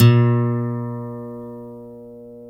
Index of /90_sSampleCDs/Roland L-CDX-01/GTR_Steel String/GTR_18 String